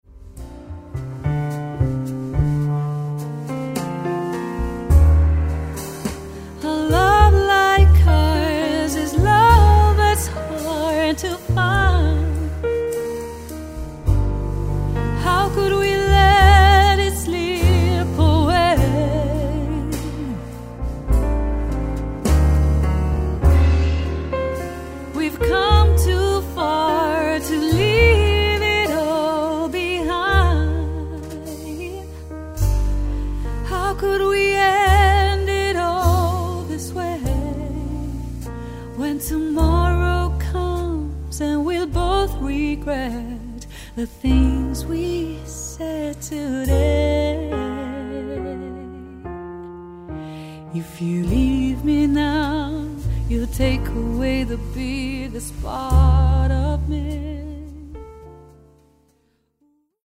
JAZZ/POP